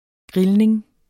Udtale [ ˈgʁilneŋ ]